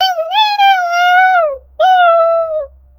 Clean, isolated vocal recordings of a zebra, 40kHz, mono WAV files, no background noise, labeled and trimmed. 0:03 Clean, isolated vocal recordings of a turtle, 40kHz, mono WAV files, no background noise, labeled and trimmed. 0:03
clean-isolated-vocal-reco-u2crvgl3.wav